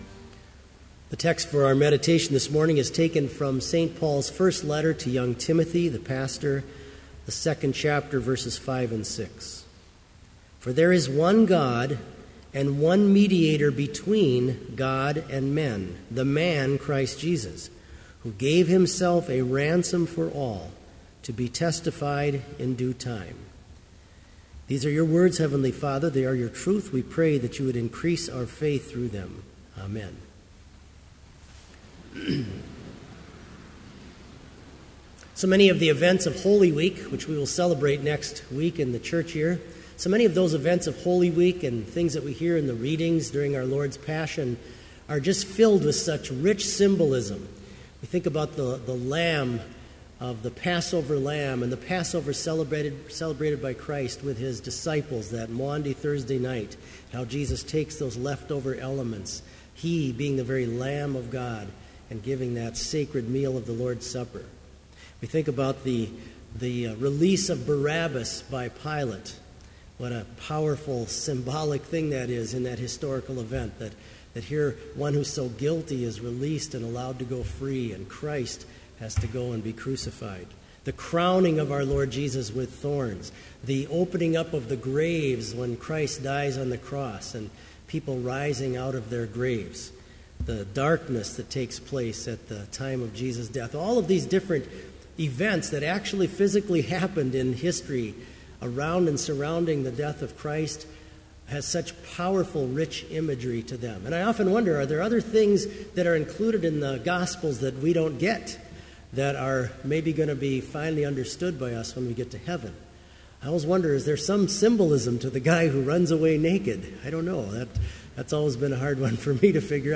Sermon Only
This Chapel Service was held in Trinity Chapel at Bethany Lutheran College on Thursday, April 10, 2014, at 10 a.m. Page and hymn numbers are from the Evangelical Lutheran Hymnary.